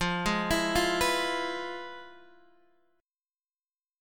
FmM7b5 chord